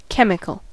chemical: Wikimedia Commons US English Pronunciations
En-us-chemical.WAV